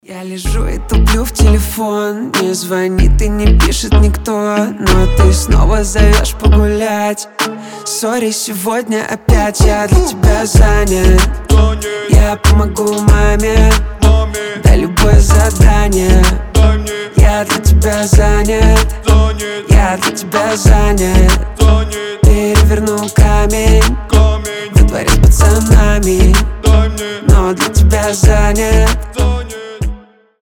• Качество: 320, Stereo
мужской голос
Хип-хоп
дуэт